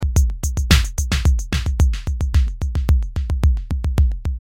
Electronic-drum-beat-loop-110-bpm.mp3